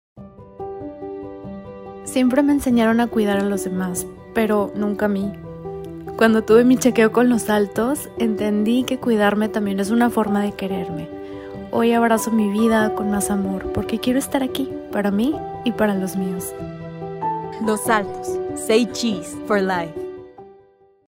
Historias reales de mujeres reales
Los nombres y voces utilizados son ficticios, con el objetivo de transmitir mensajes universales de prevención y esperanza.
testimonial-3-v2.mp3